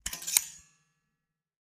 fo_stapler_spring_01_hpx
Papers are stapled together. Paper, Staple